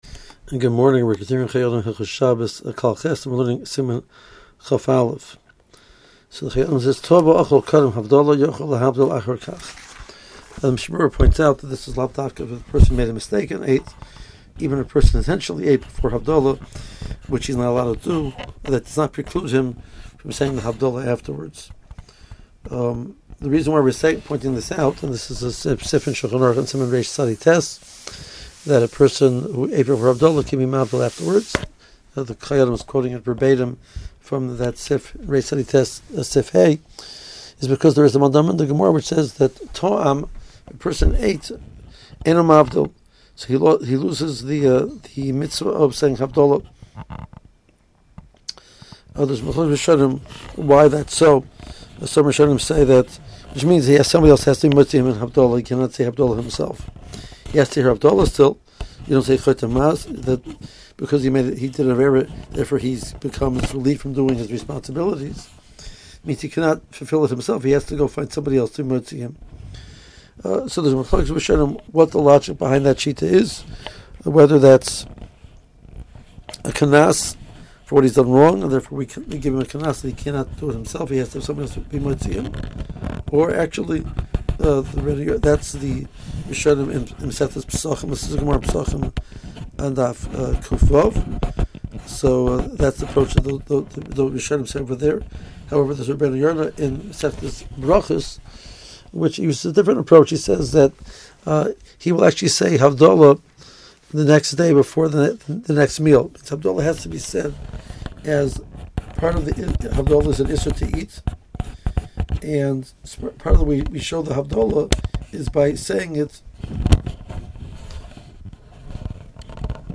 • 5 Min. Audio Shiur Including Contemporary Poskim